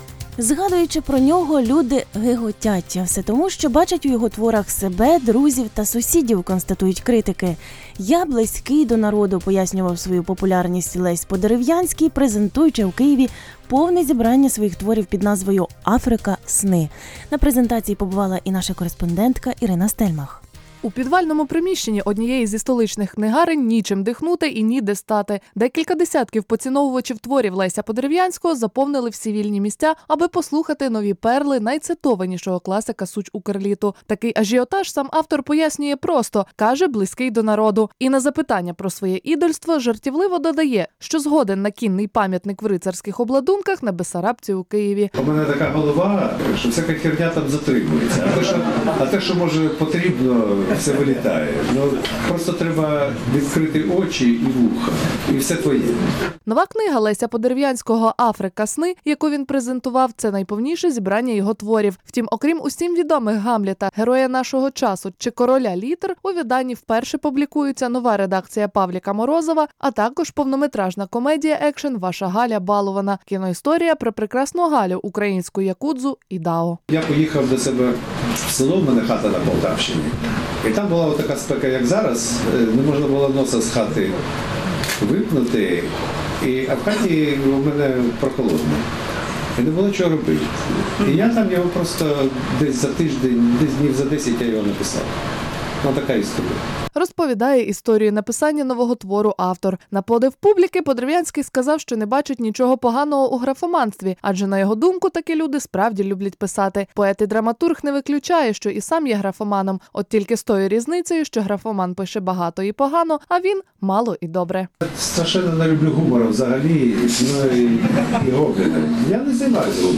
Лесь Подерв’янський під час презентації книги «Африка. Сни», 9 липня 2015 року
У підвальному приміщенні однієї зі столичних книгарень нічим дихнути, ніде стати.